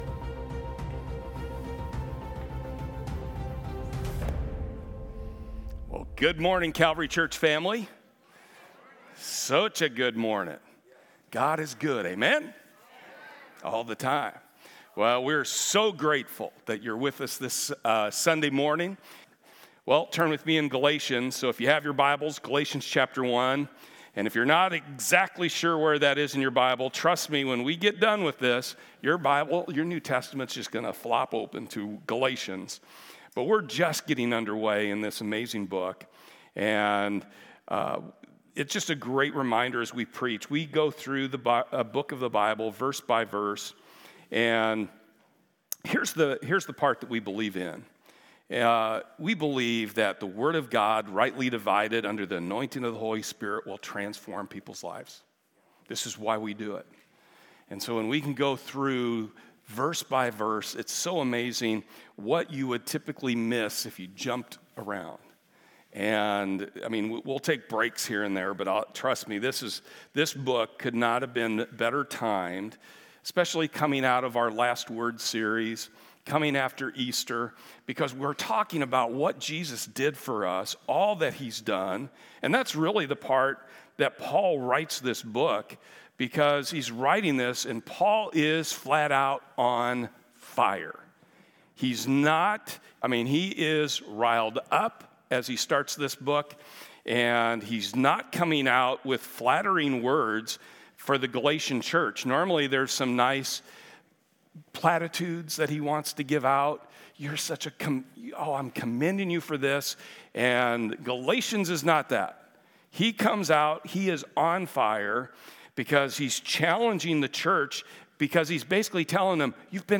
The Book of Galatians Service Type: Sunday https